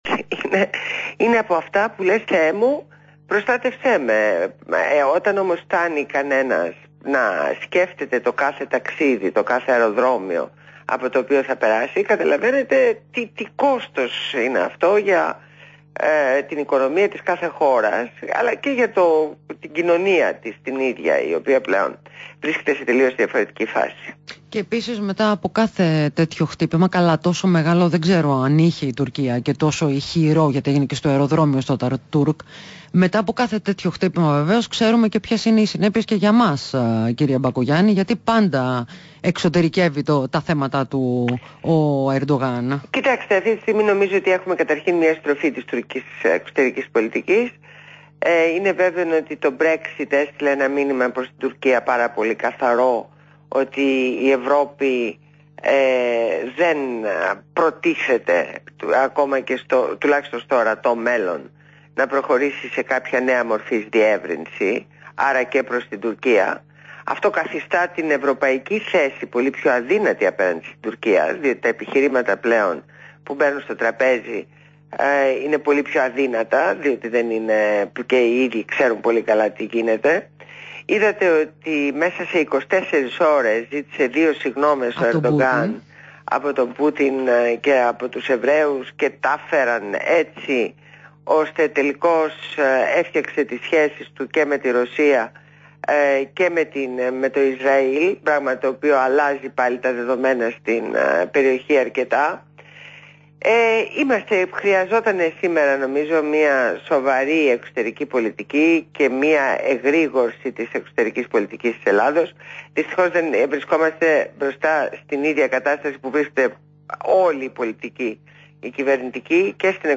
Συνέντευξη
στο ραδιόφωνο REALfm